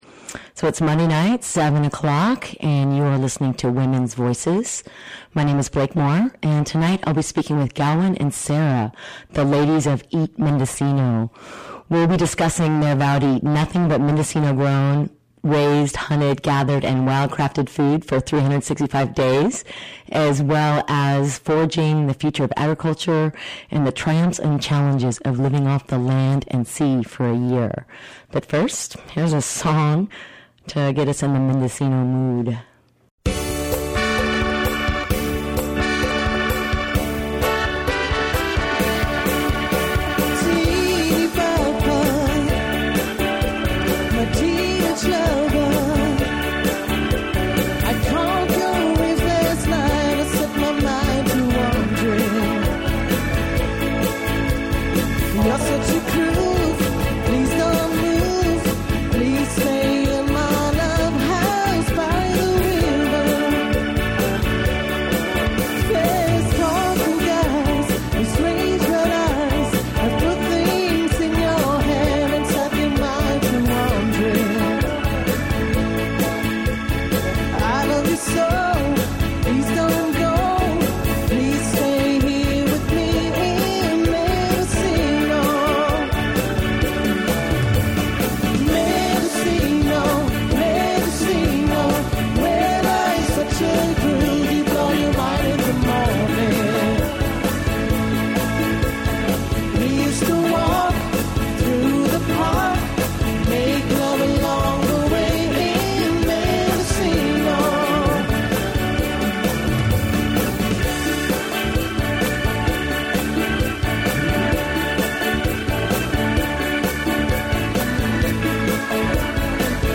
Hey guys, Did you catch our interview on Women’s Voices on KZYX?